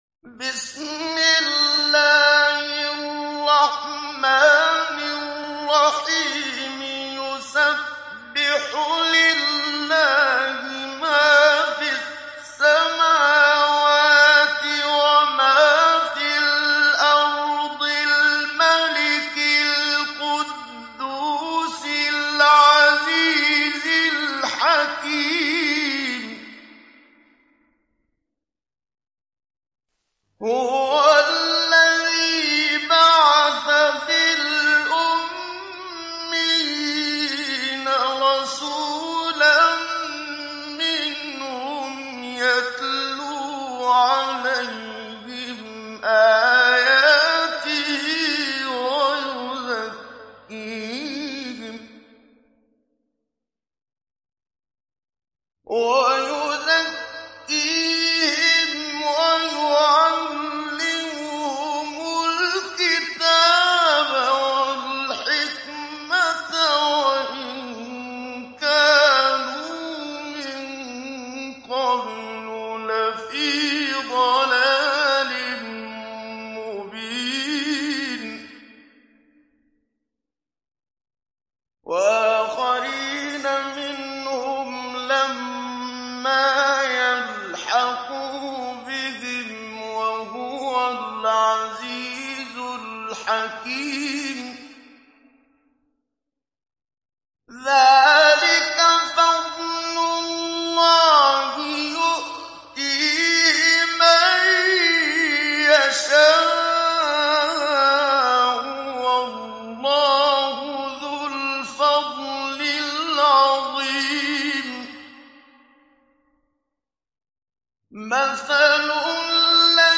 İndir Cüma Suresi Muhammad Siddiq Minshawi Mujawwad